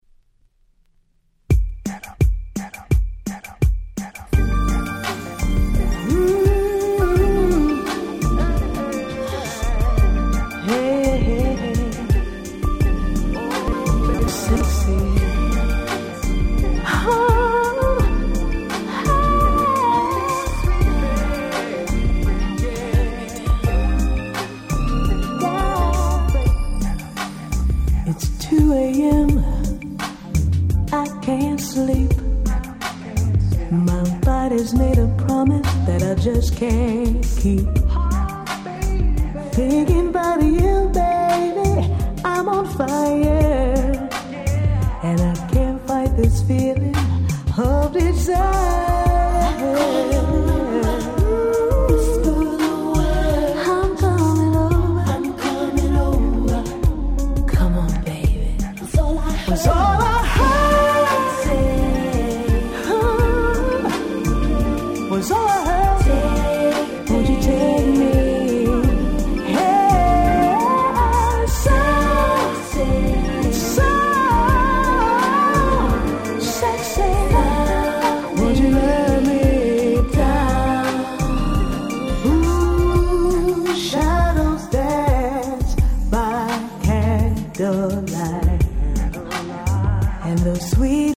96' Nice UK R&B !!